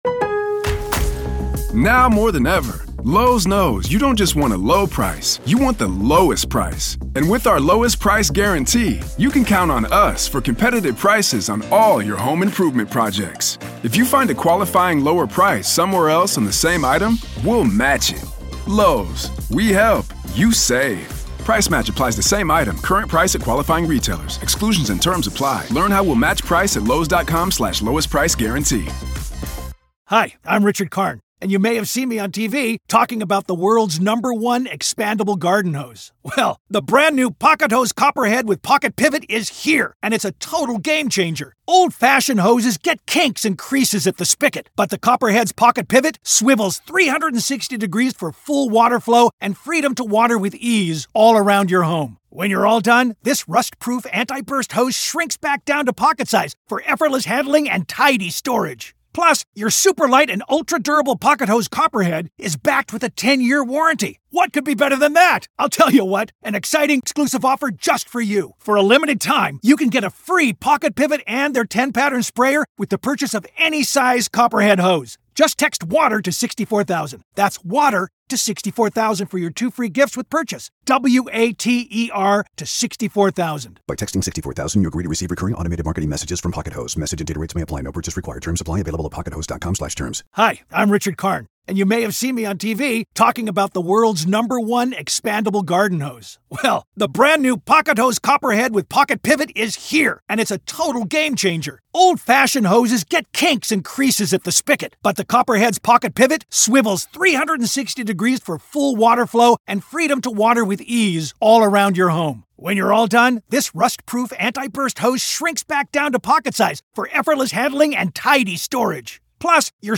The interview examines how successful defense teams build rapport with juries through authenticity and reasonable doubt based on evidence, versus the Karen Read team's approach of attacking investigators, promoting elaborate conspiracy theories, and avoiding discussion of physical evidence.